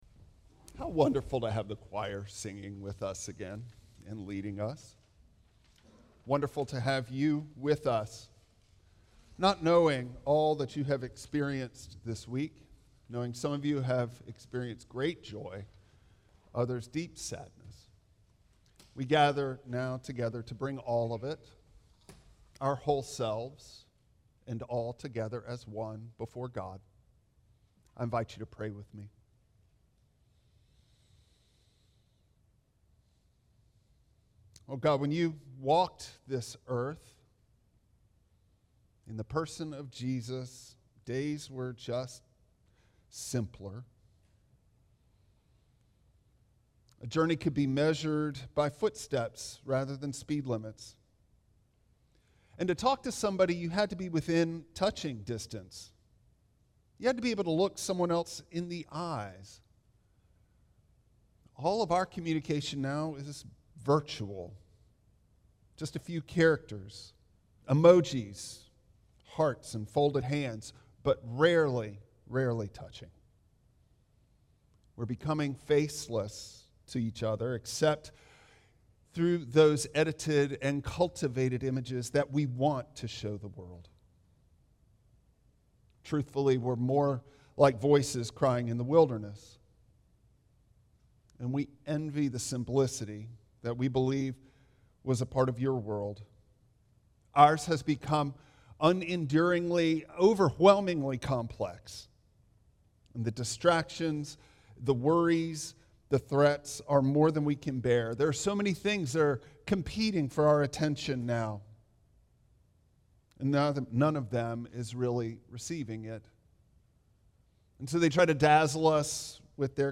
Passage: 2 Corinthians 3:12-4:2 Service Type: Traditional Service